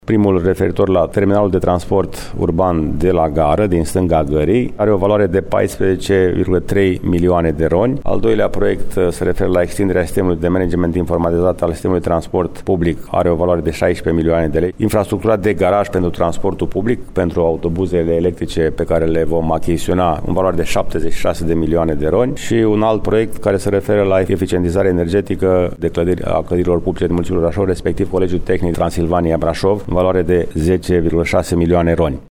Care sunt proiectele suplimentare din municipiu care vor fi finanțate cu bani europeni, ne spune primarul municipiului Brașov, George Scripcaru: